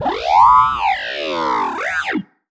sci-fi_driod_robot_emote_neg_04.wav